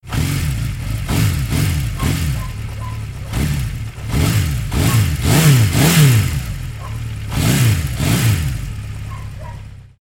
دانلود صدای گاز دادن موتور سیکلت از ساعد نیوز با لینک مستقیم و کیفیت بالا
جلوه های صوتی